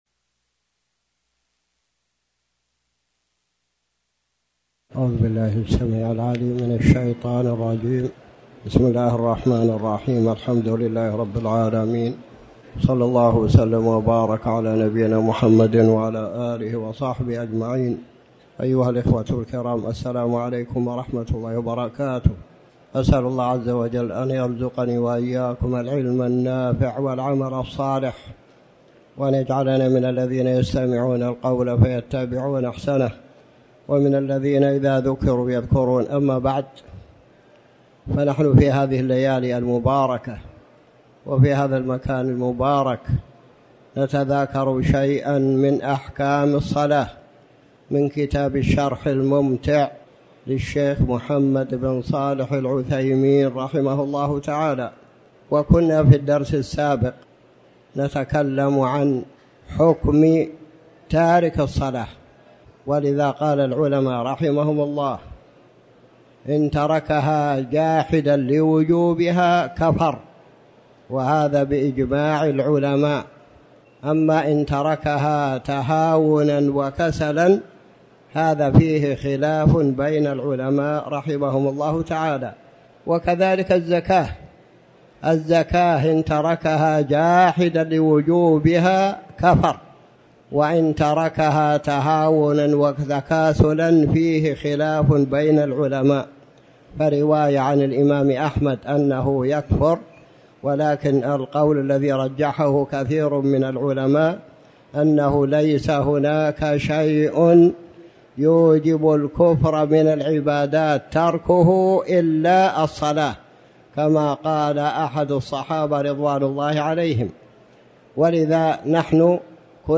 تاريخ النشر ٨ صفر ١٤٤٠ هـ المكان: المسجد الحرام الشيخ